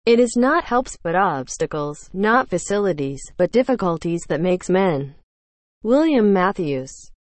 (Text-to-Speech by Sound of Text, using the engine from Google Translate)